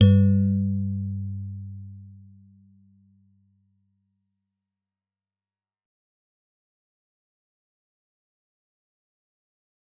G_Musicbox-G2-f.wav